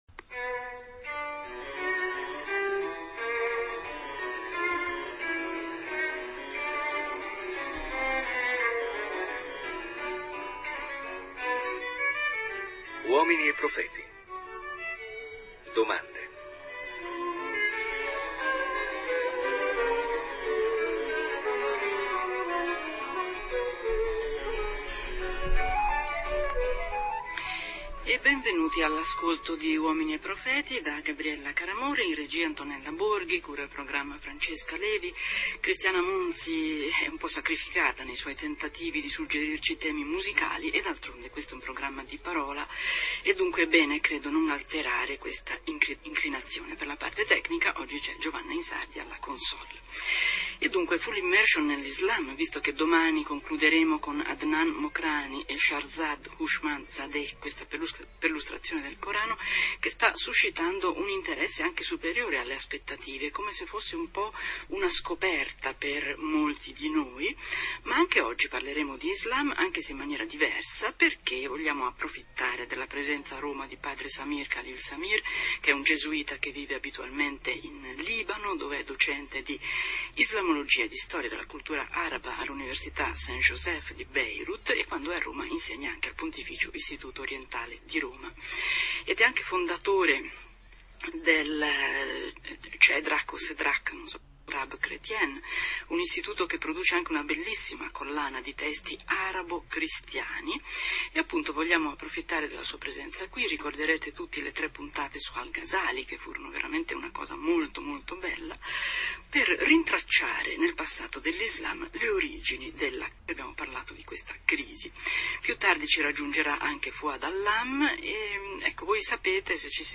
da "uomini e profeti" trasmissione di radio tre.